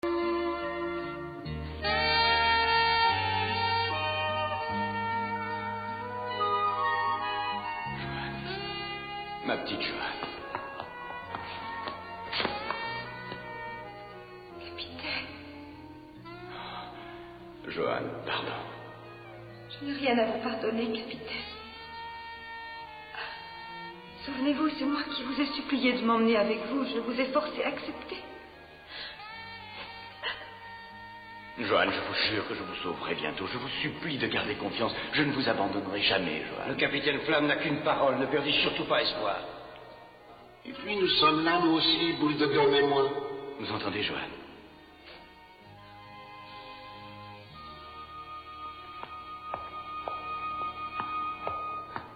Sur la planète Tarust Johann est condamnée en premier à subir le châtiment de l'âme abjecte séparant l'âme du corps, soit pratiquement la peine de mort. Ce passage est par conséquent d'une forte intensité dramatique (à vos mouchoirs !).